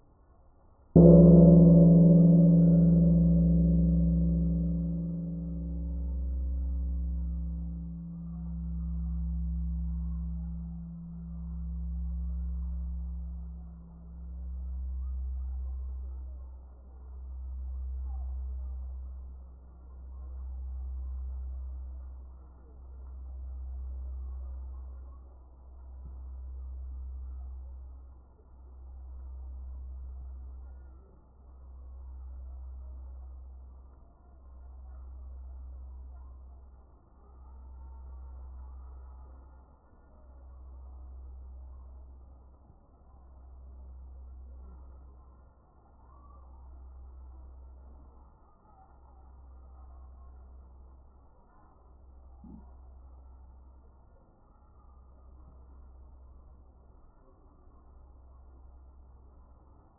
PS. 전에 받아둔 타종소리 첨부파일로 올려봅니다.
정말 1분동안 울림이 지속되는군요.